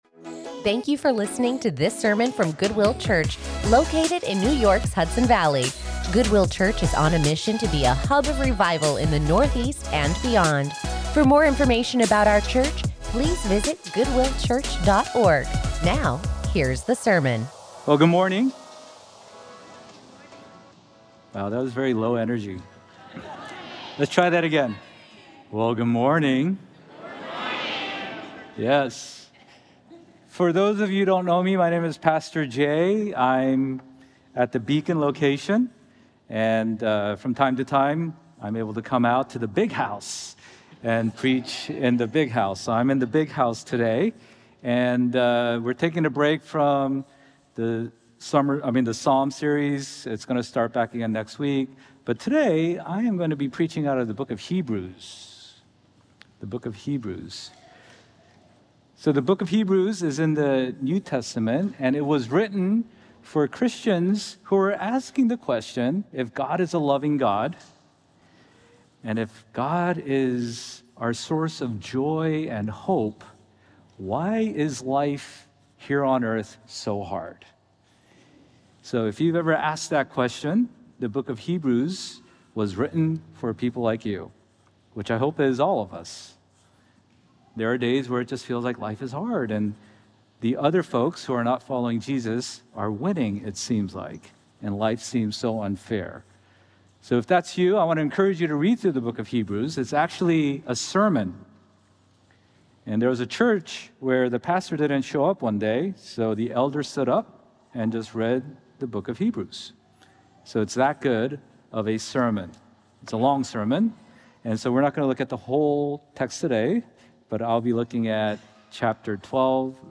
Join us in studying God's Word as we take a break from our sermon series with this sermon